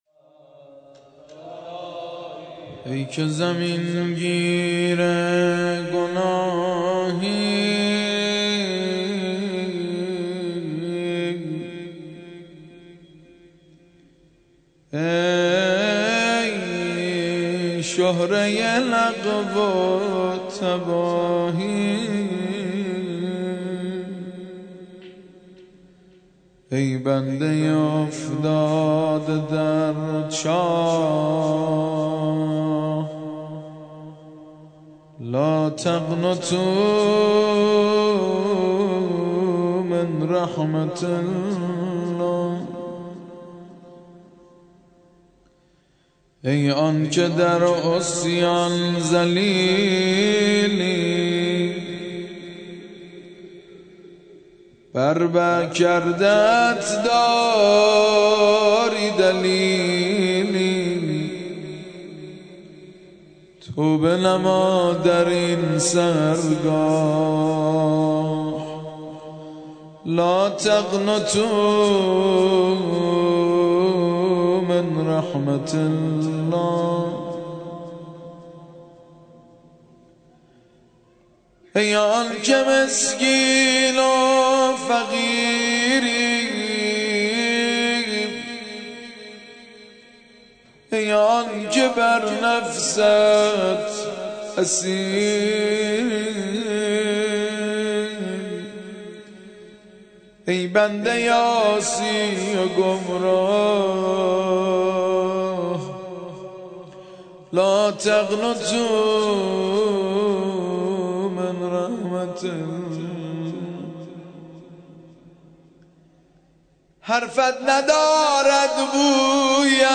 مراسم شب هجدهم ماه رمضان با مداحی
درمسجد کربلا برگزار گردید.